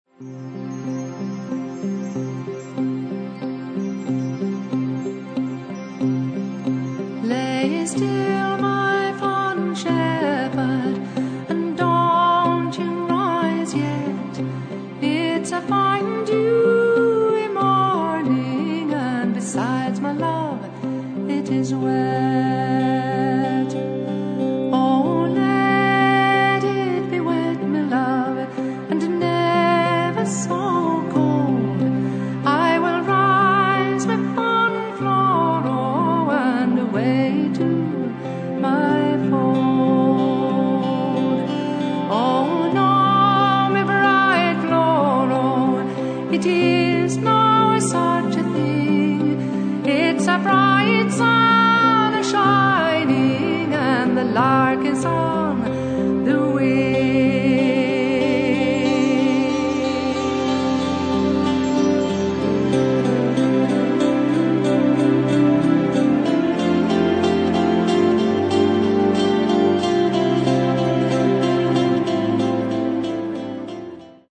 First part, 0:38 sec, mono, 22 Khz, file size: 363 Kb.